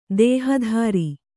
♪ dēha dhāri